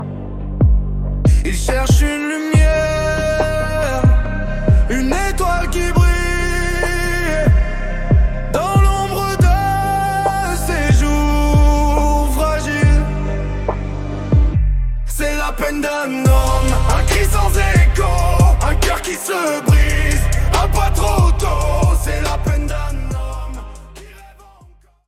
Style : Rap